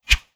Close Combat Swing Sound 40.wav